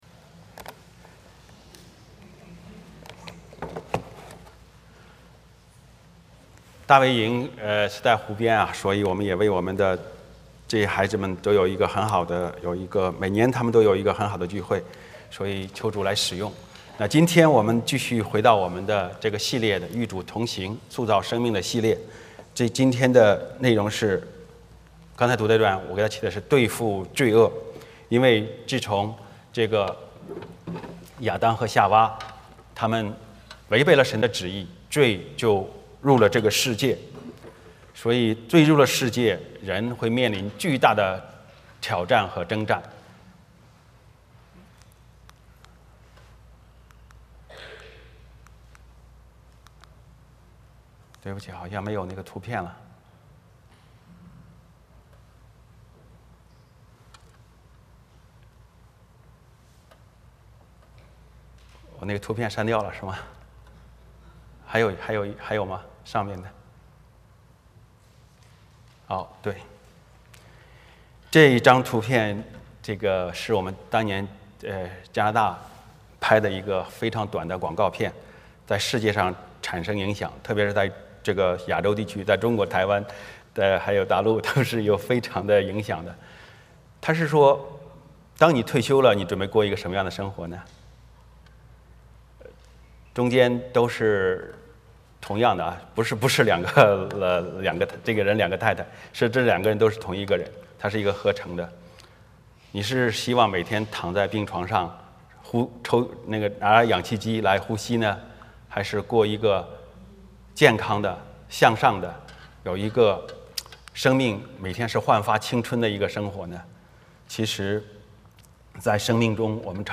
欢迎大家加入我们国语主日崇拜。
16-24 Service Type: 圣餐主日崇拜 欢迎大家加入我们国语主日崇拜。